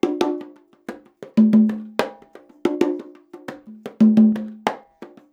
90 CONGA 2.wav